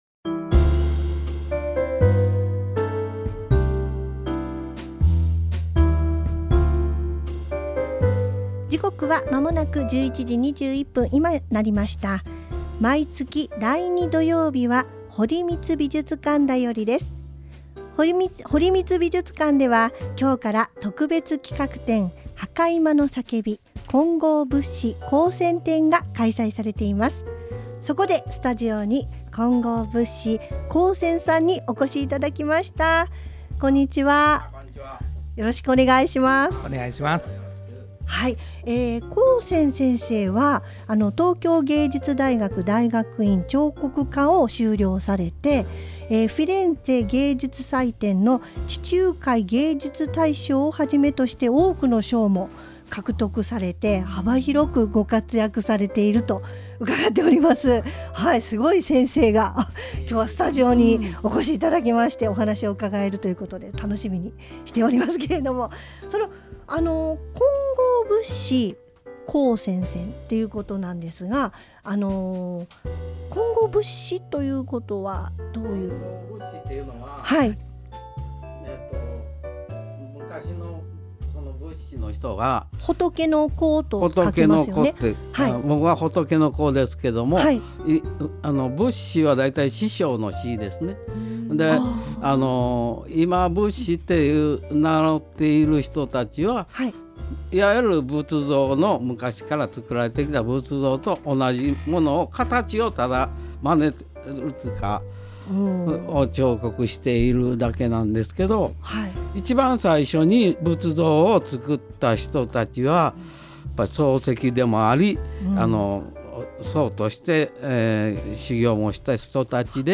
ラジオ番組